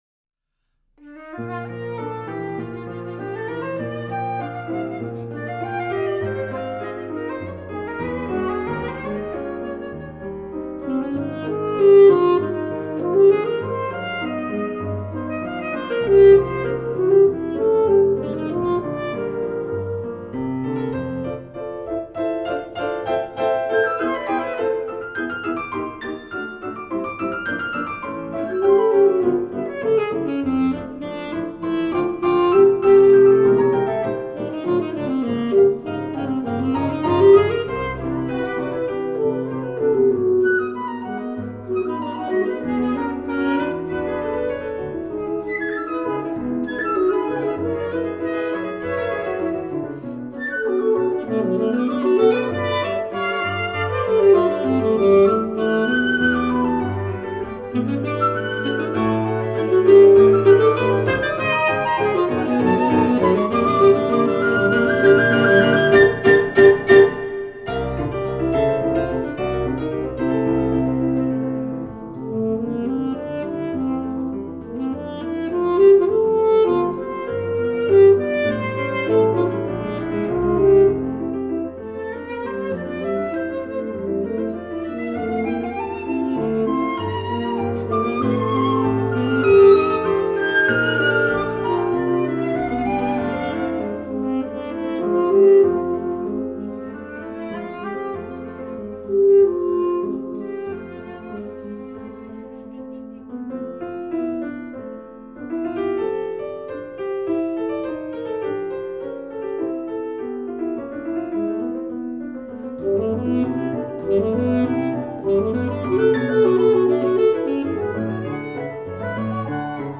And this is indeed a sunny, unpretentious work.